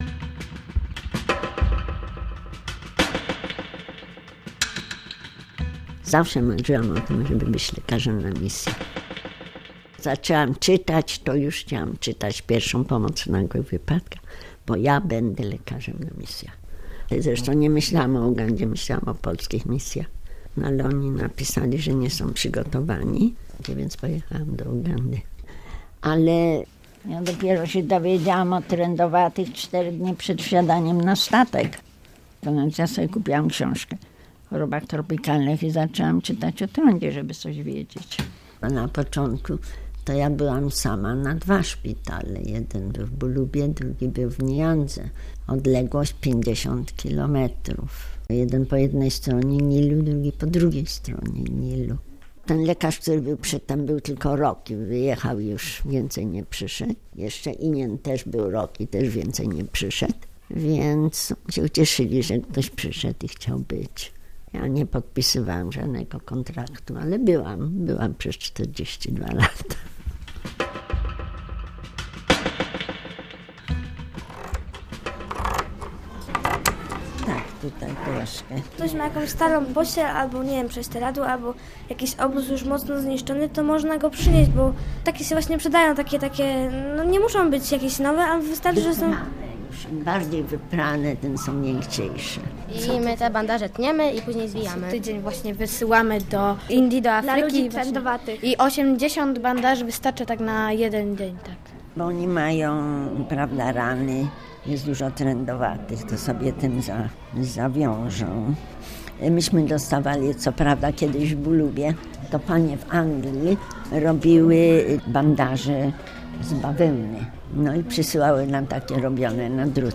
Bez kontraktu - reportaż